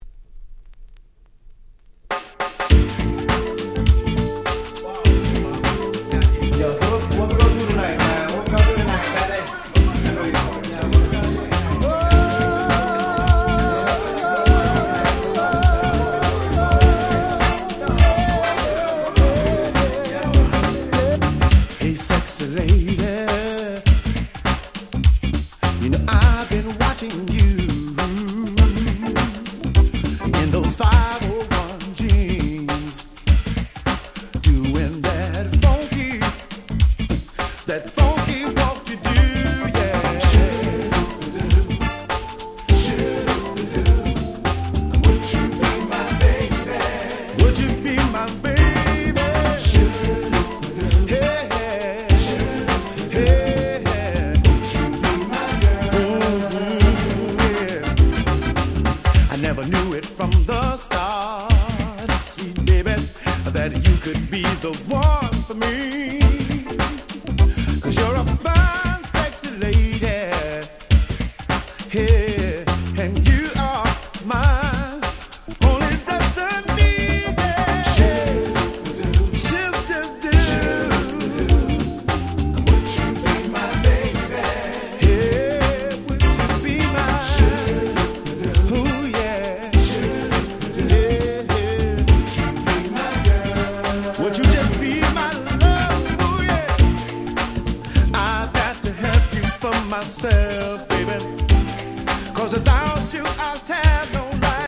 95' Very Nice R&B / New Jack Swing !!
90's NJS ハネ系